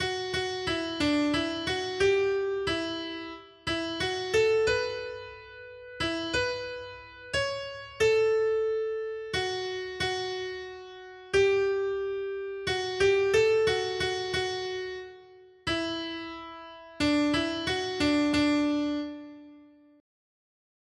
Noty Štítky, zpěvníky ol565.pdf responsoriální žalm Žaltář (Olejník) 565 Skrýt akordy R: Zachovej mi život, Pane, a budu dbát na tvá přikázání. 1.